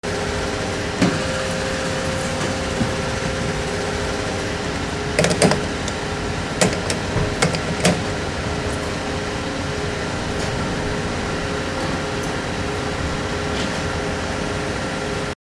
Gemafreie Sounds: Tankstelle
mf_SE-7089-petrol_pump_closeup.mp3